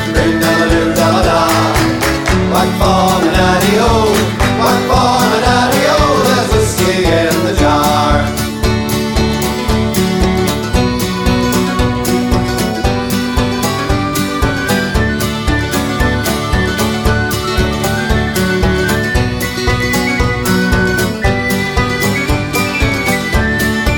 No Backing Vocals Irish 2:24 Buy £1.50